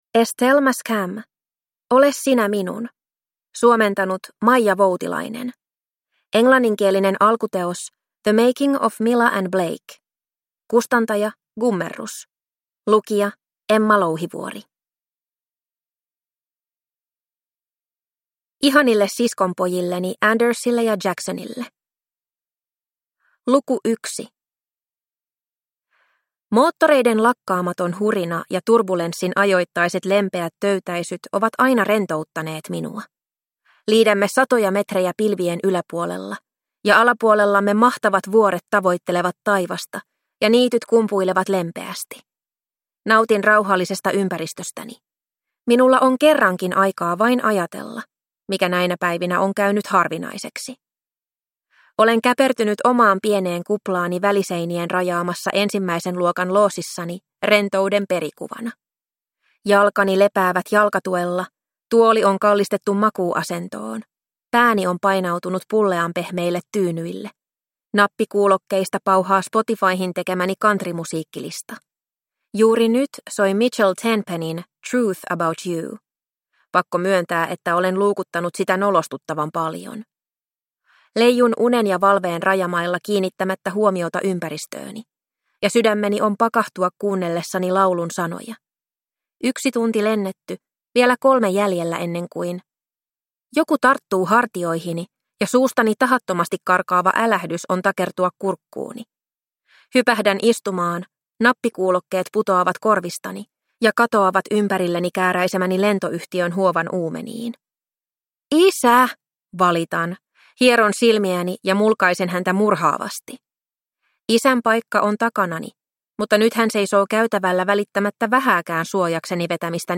Ole sinä minun – Ljudbok